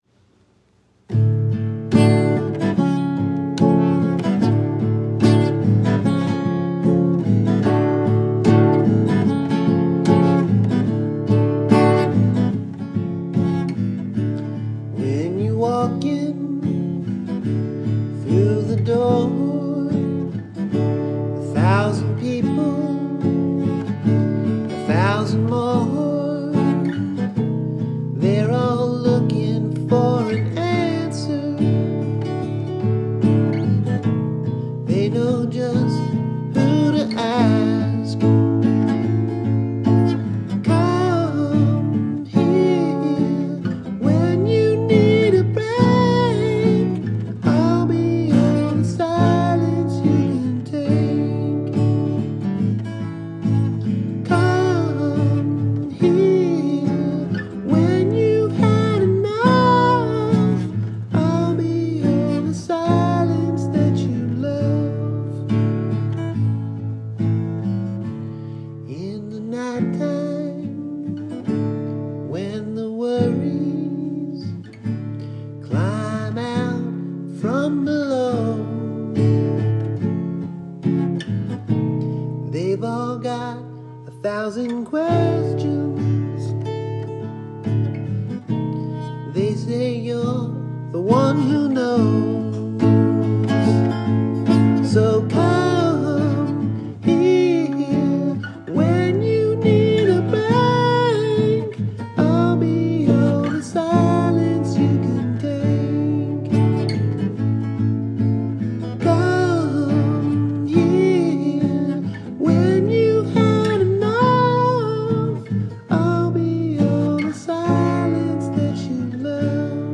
guitar recording